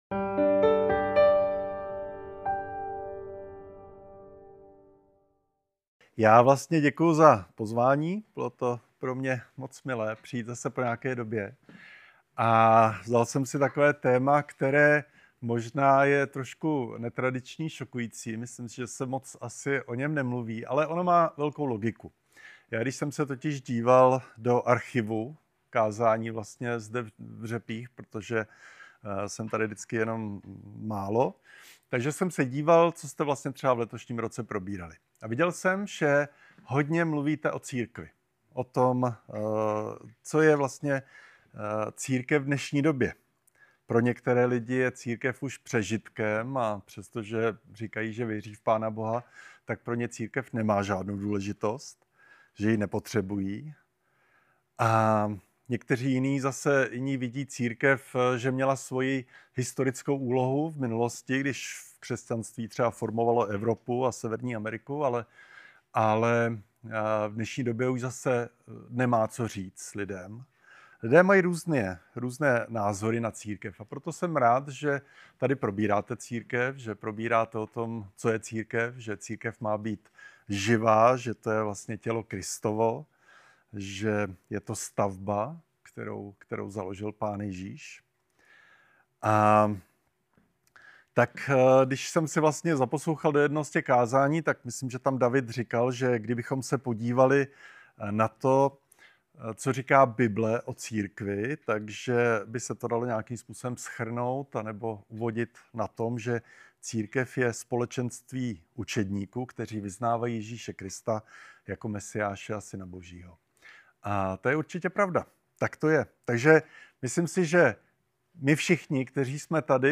Kázání | Pochodeň Praha